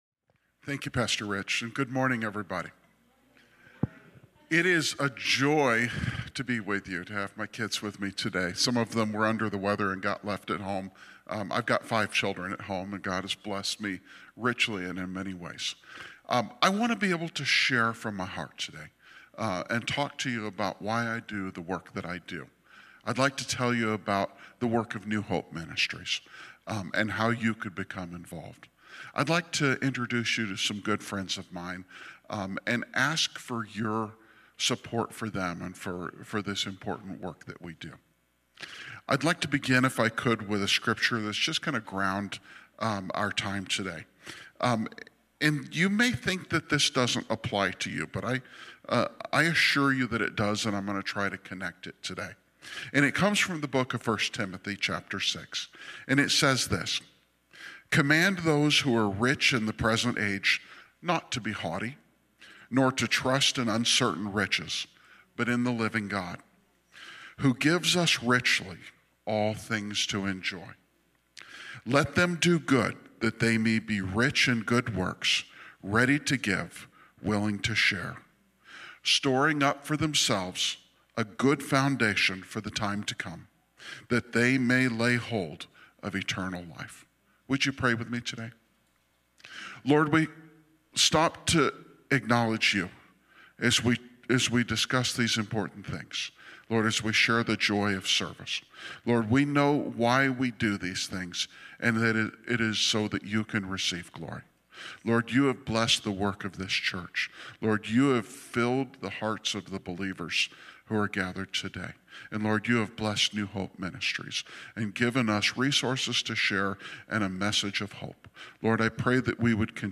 Cornerstone Fellowship Sunday morning service, livestreamed from Wormleysburg, PA.